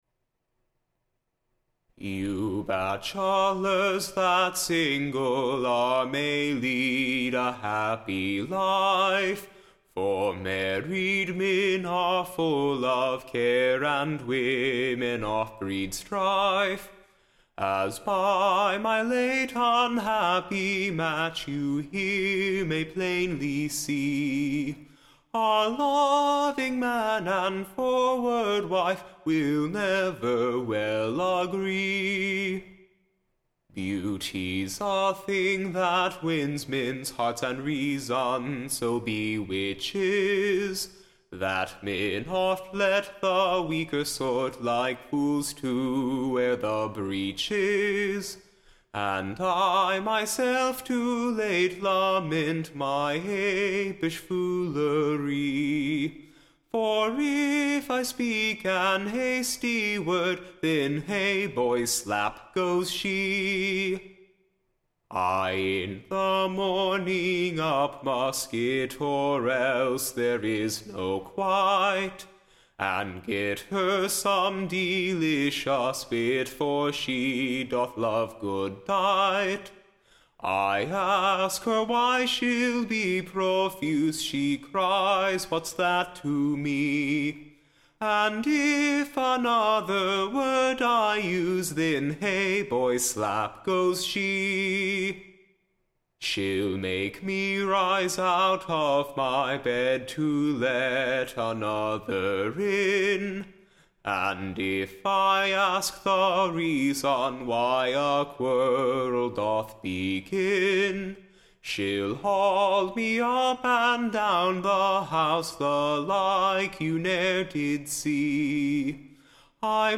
Ballad Title Advice to Batchelors, / OR, / The Married Mans Lamentation.